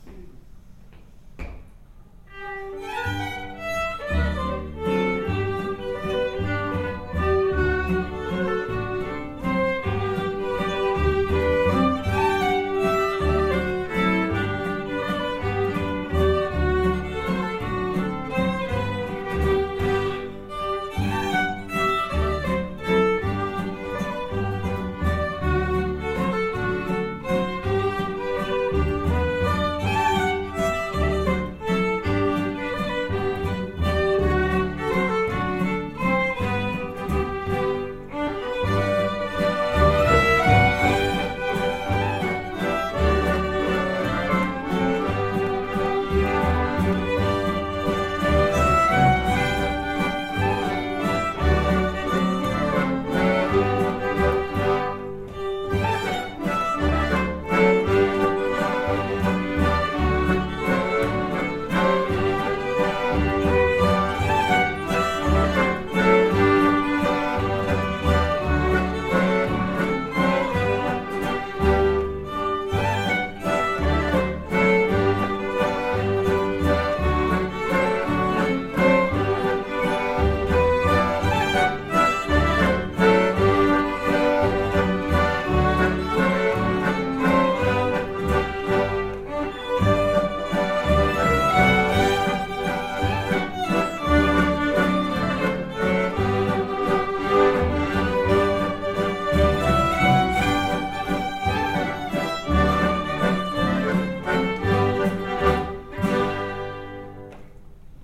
Nauhoitimme kilpailukappaleemme viimeisessä harjoituksessa ennen kilpailua.